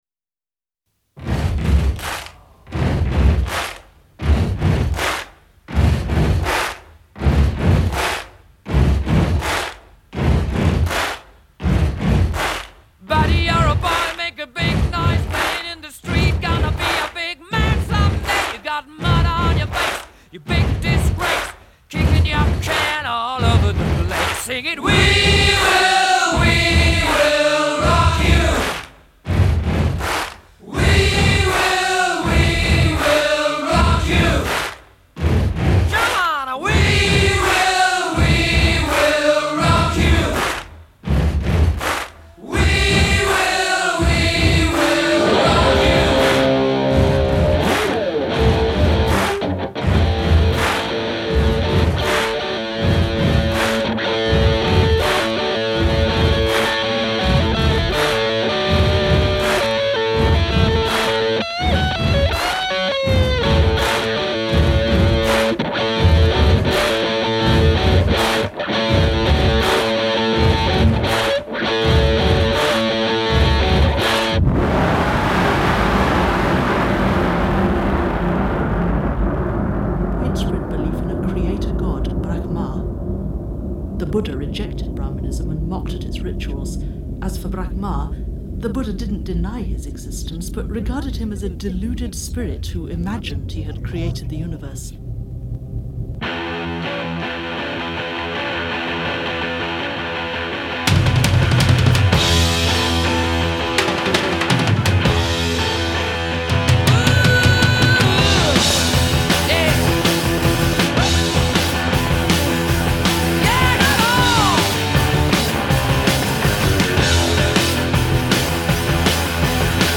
in a London studio performing live for the BBC